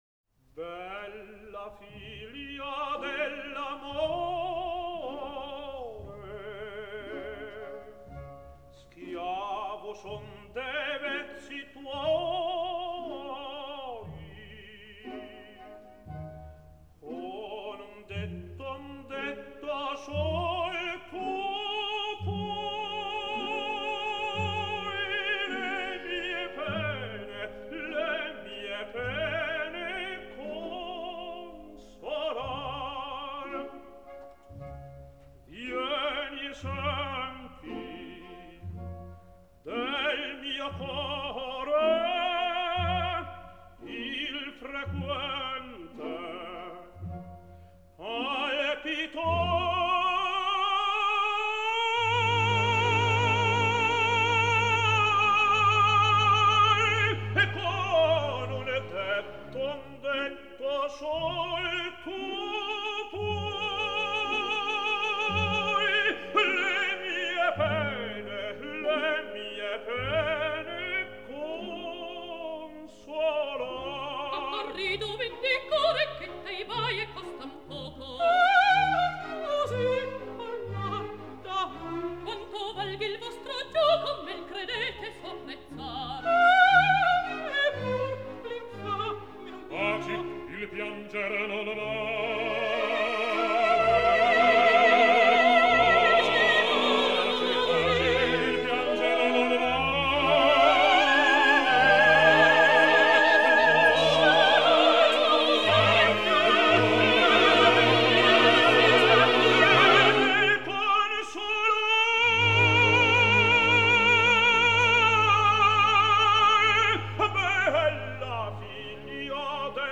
Квартет из оперы Риголетто Д.Верди с участием К.Бергонци, Р.Скотто и Д.Фишер-Дискау